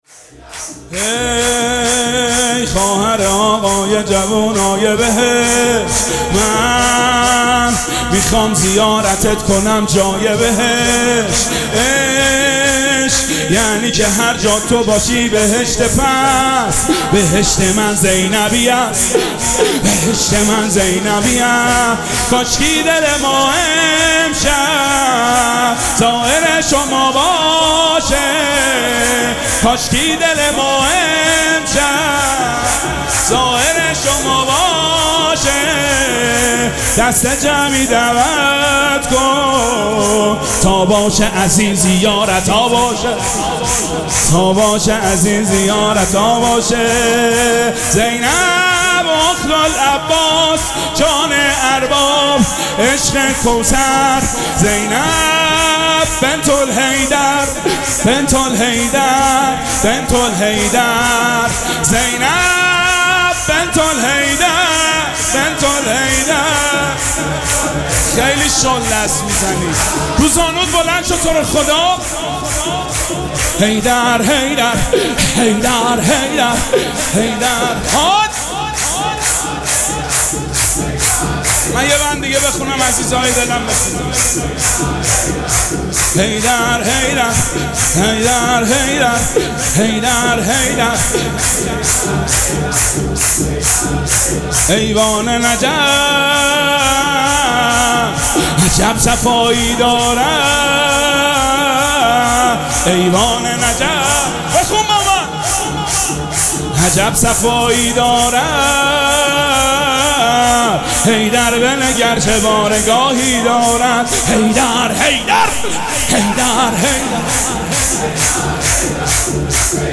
مراسم جشن ولادت حضرت زینب سلام‌الله‌علیها
سرود